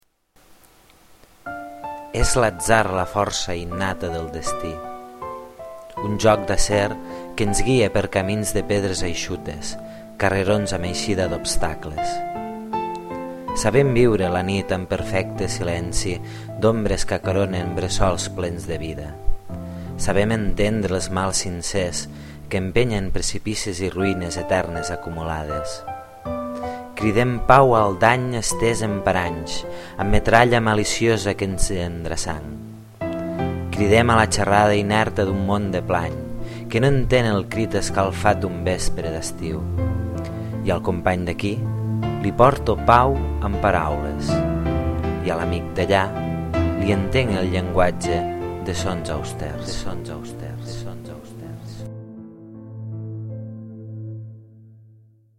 Preciós el poema, molt bonica la música i… estàs fet un rapsode de primera.